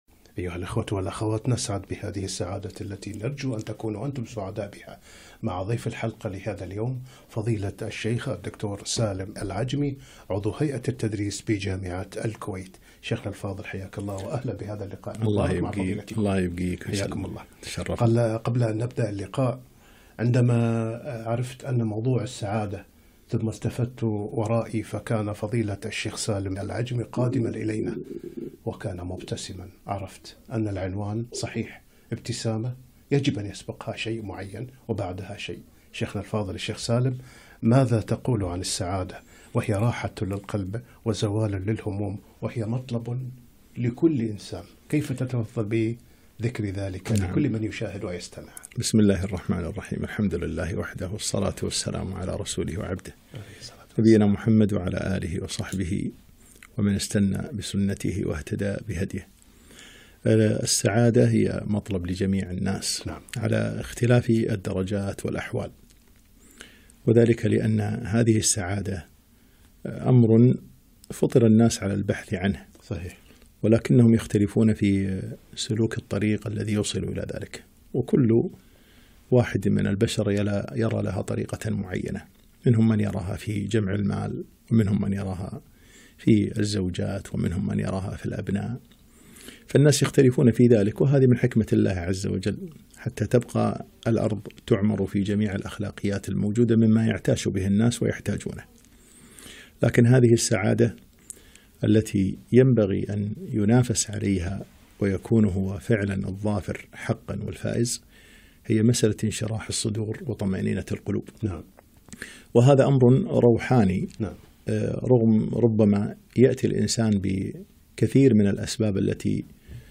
لقاء إذاعي عن ( السعادة) في إذاعة القرآن الكريم 7-7-1442